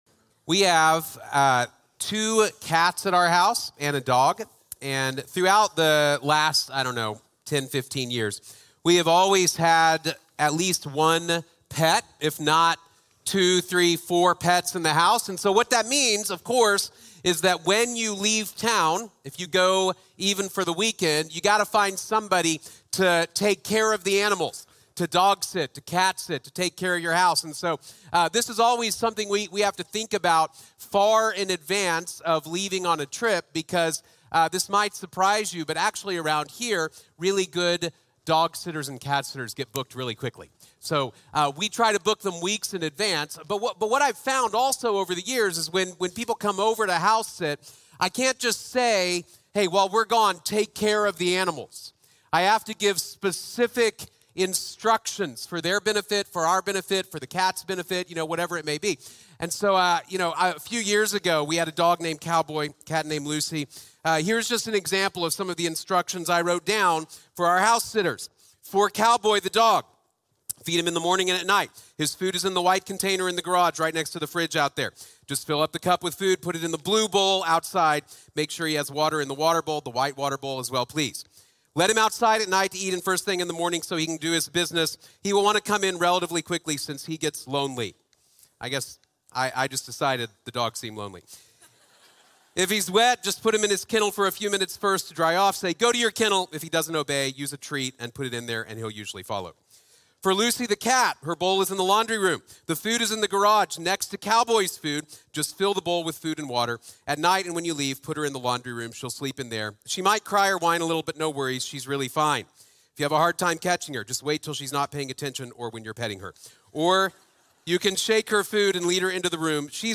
A Life on Mission | Sermon | Grace Bible Church